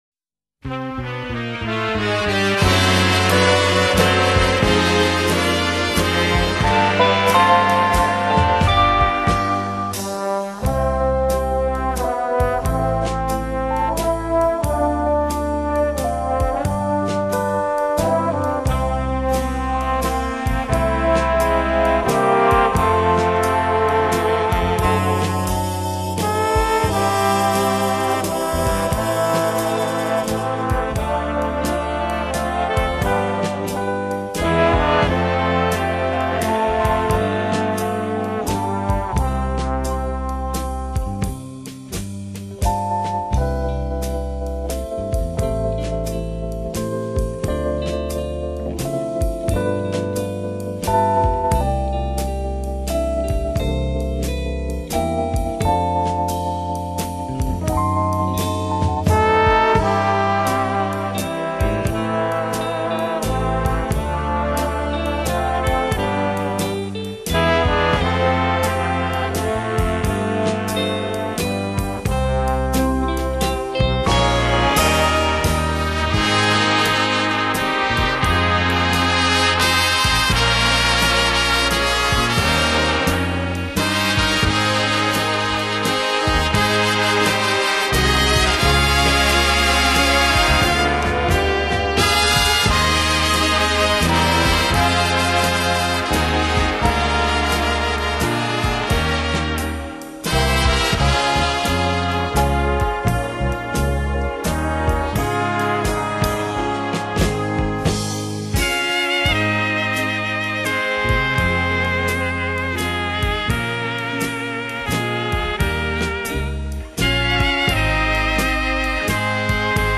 2、精心运用打击乐、尤其是一些特殊的打击乐器，小号、 甚至是口哨声都被其纳入。3、乐队演奏以华丽著称，气势磅礴。
(Langsamer Walzer)